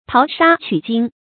淘沙取金 táo shā qǔ jīn
淘沙取金发音